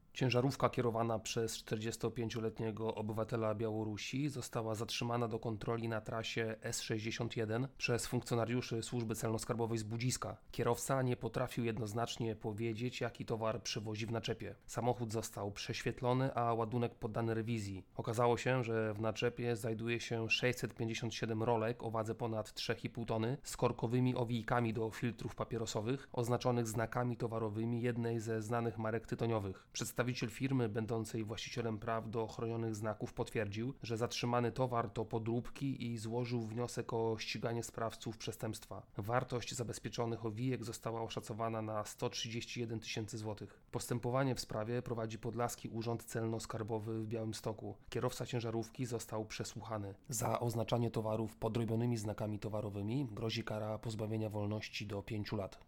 wypowiedź oficera podlaskiej KAS